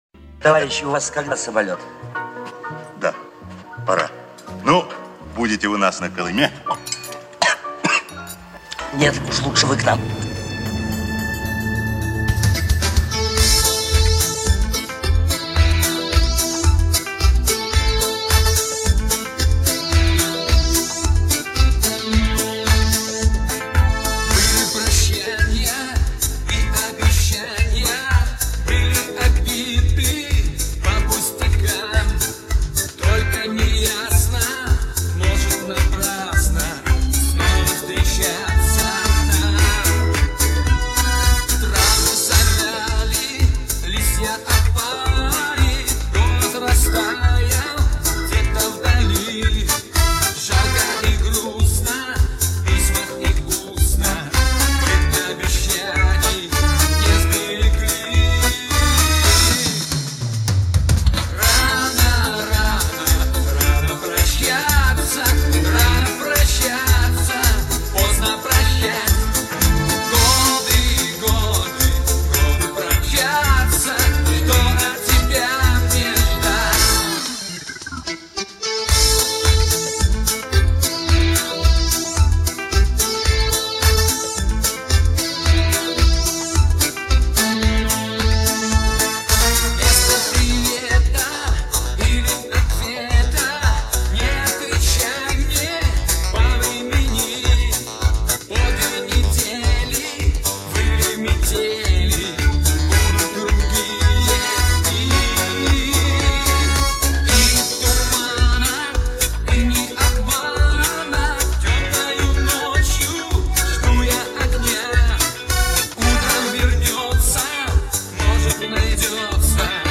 ...стерео не профи -результат так себе.